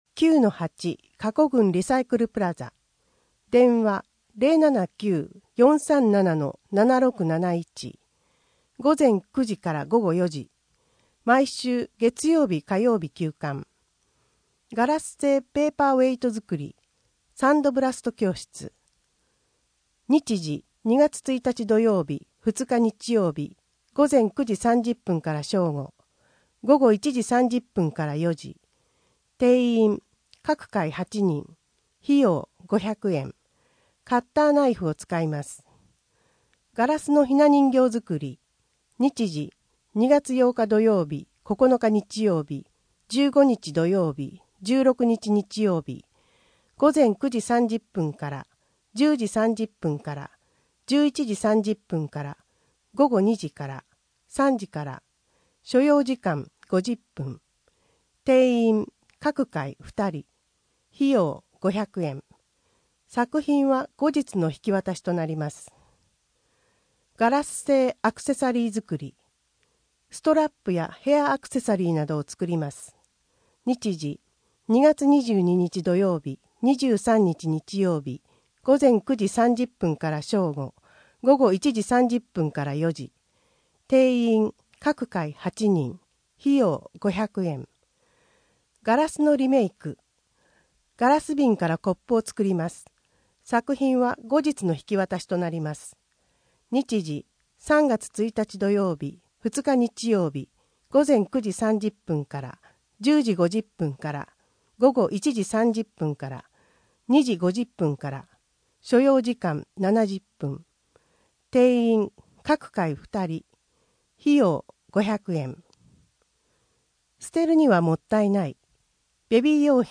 声の「広報はりま」2月号
声の「広報はりま」はボランティアグループ「のぎく」のご協力により作成されています。